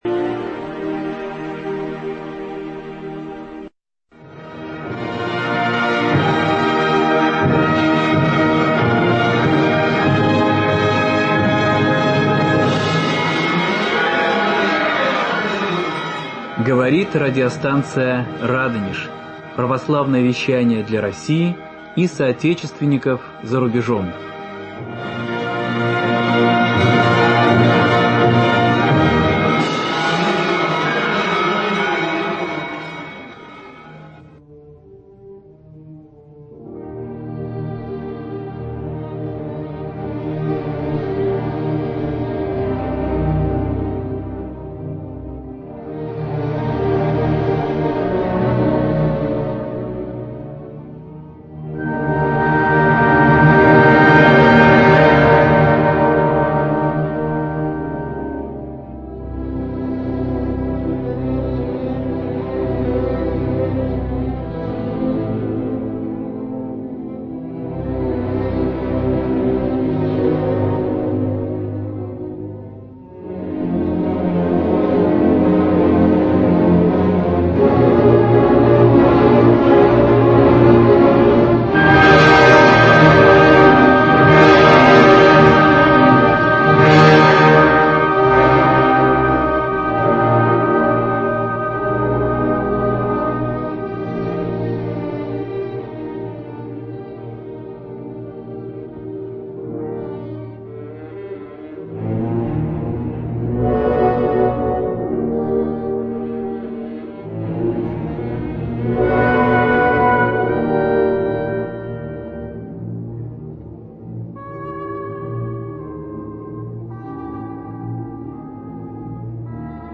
Продолжение вечера в БЗ МК (фрагменты). Фрагменты письма К.С.Станиславского – А.В.Н. читает А.Степанова. Музыкальные номера в исполнении оркестра, вокалистов, фортепиано (С. Рихтер). Стихи А.С. Пушкина читает М. Царёв.